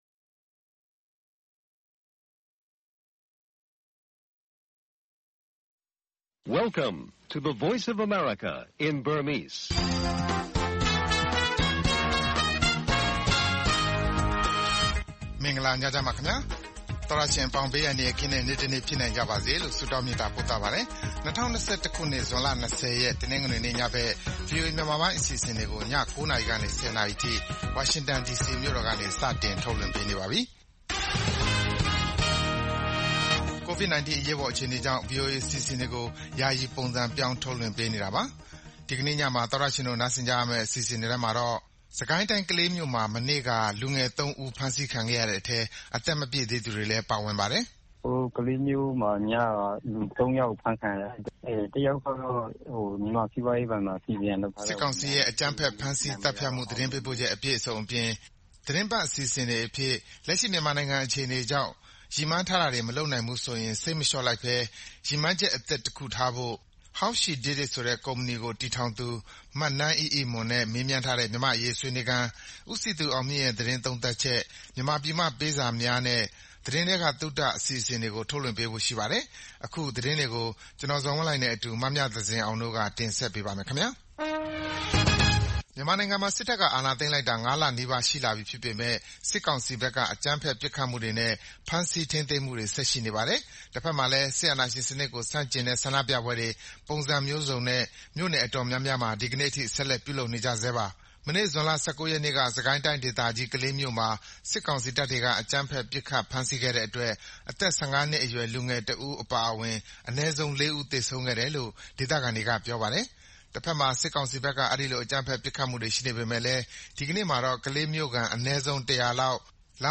VOA ညပိုင်း ၉း၀၀-၁၀း၀၀ တိုက်ရိုက်ထုတ်လွှင့်ချက်